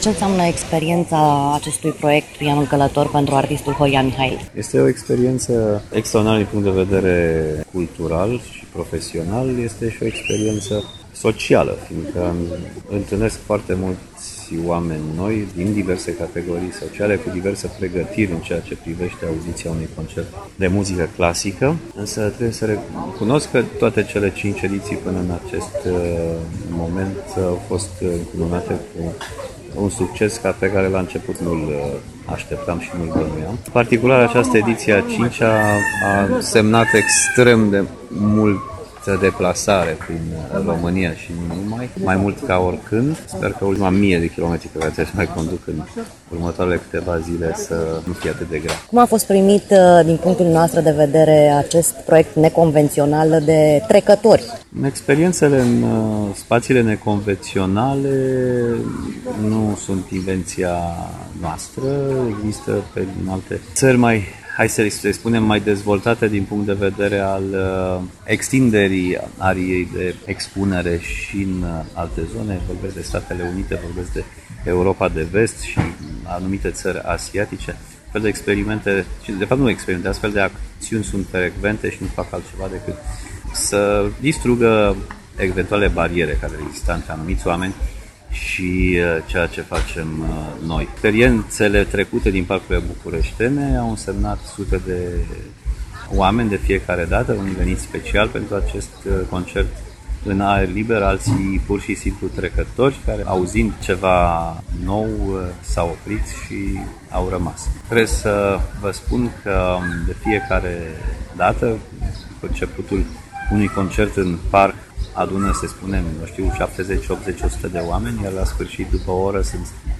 a realizat un scurt interviu cu pianistul Horia Mihail
Interviu-Horia-Mihail.mp3